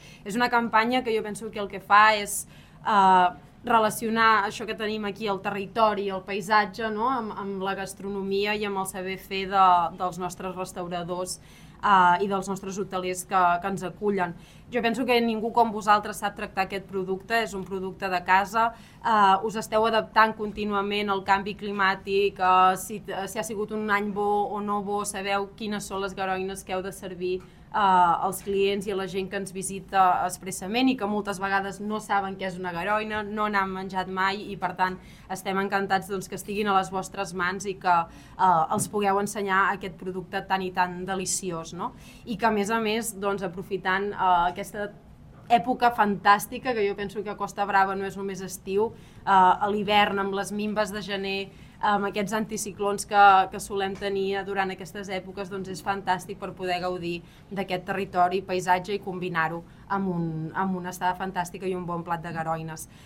La Garoinada consolida així el seu paper com un referent de la gastronomia i el turisme a la Costa Brava, combinant cuina local, cultura i paisatge, fora de la temporada estival tal com apunta l’alcaldessa.